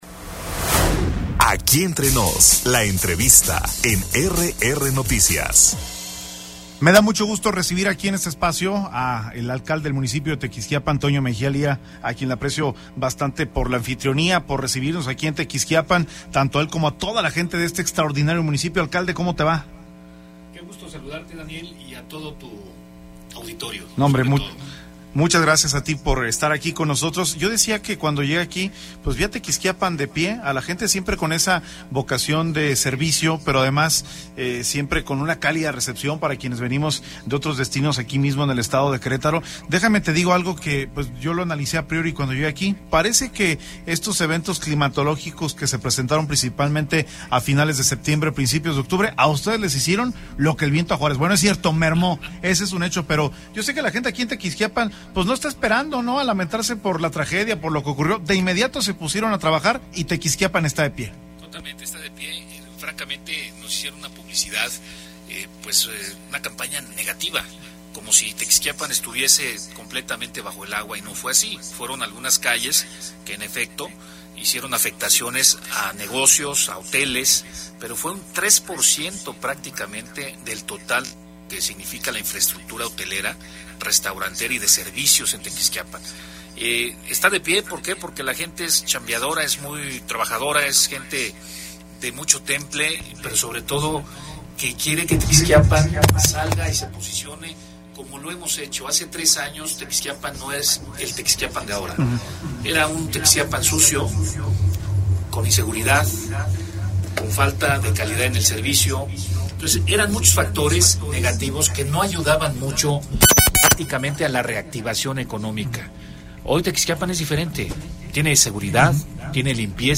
EntrevistasPodcast